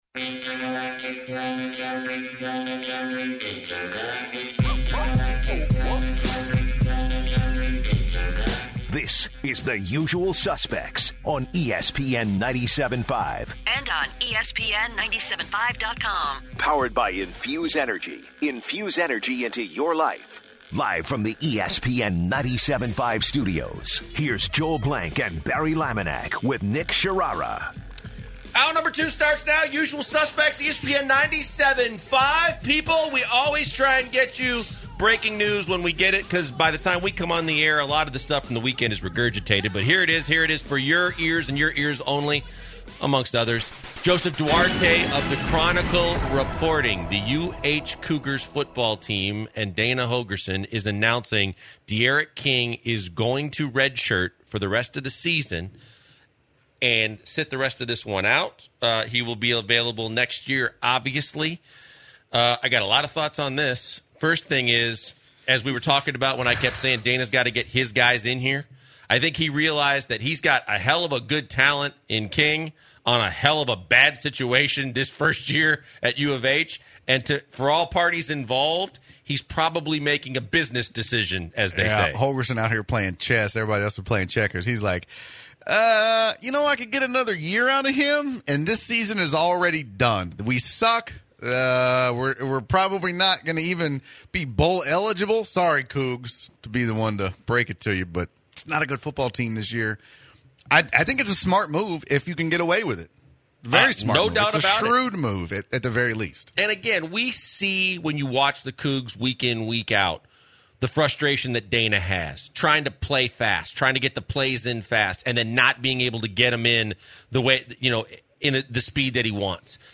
Then, they give away two tickets to Oktoberfest to lucky number caller 12! After, they listen to a clip of Astros Coach A.J. Hinch, delivering his post-game speech to the team after Sunday’s conference win.